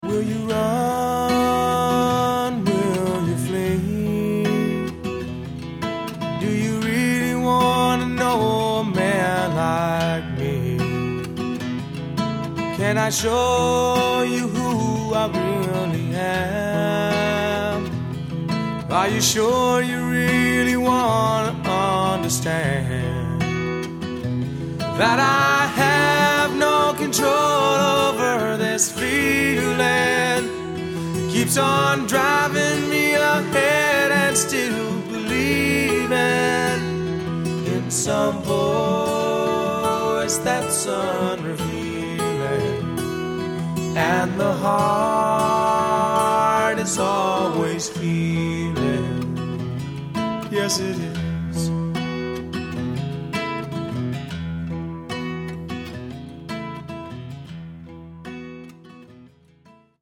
Lead vocals, keyboards.
Guitars, vocals, harmonica.
Bass, vocals.
Drums, percussion.